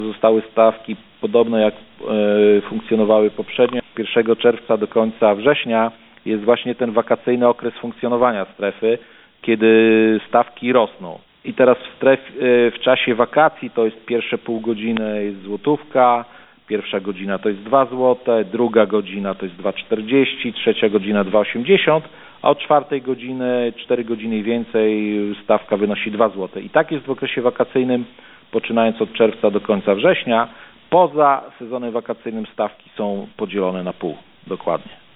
– Warto zaznaczyć, że stawki w okresie pozawakacyjnym będą o połowę niższe, niż w letnim – mówi w rozmowie z Radiem 5 Mirosław Karolczuk, burmistrz Augustowa.